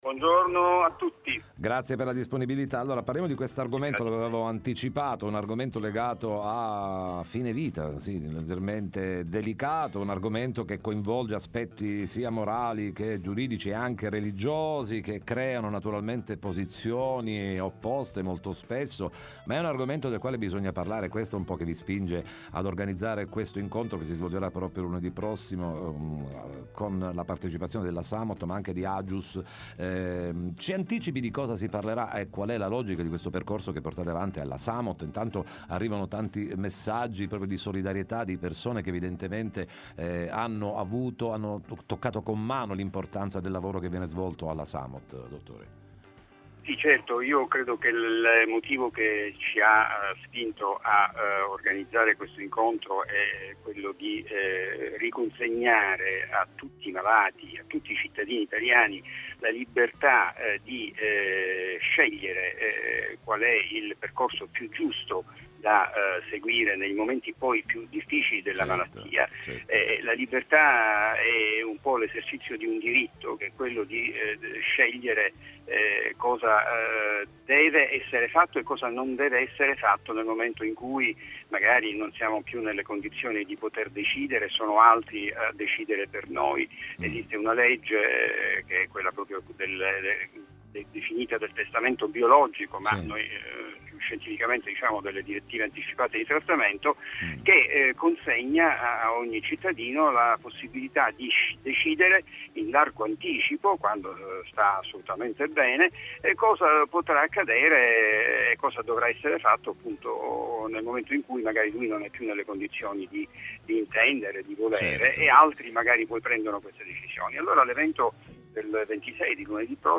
Fine vita e suicidio assistito, ne parliamo con il dott. Giorgio Trizzino, medico